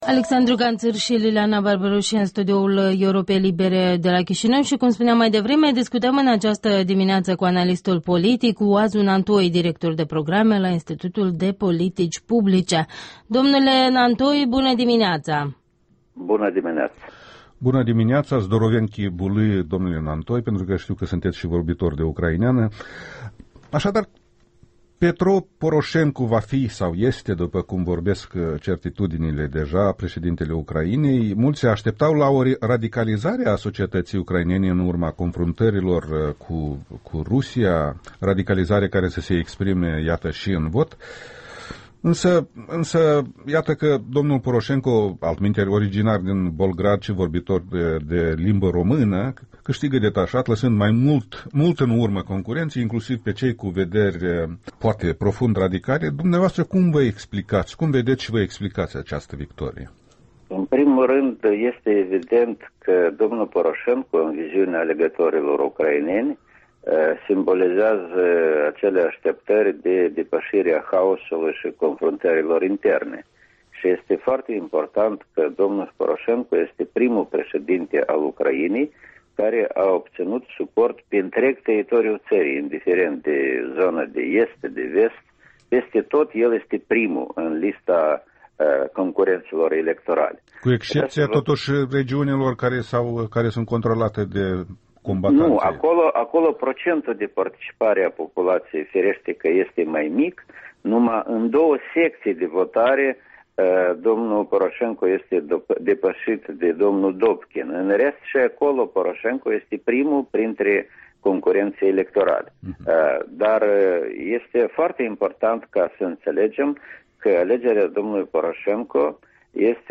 Interviul dimineții: cu Oazu Nantoi (IPP)